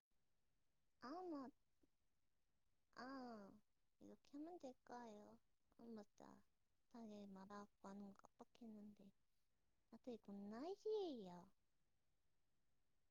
[02:08] 휴대폰 녹음이라 약간 소리가 구린 건 안 비밀이애용~!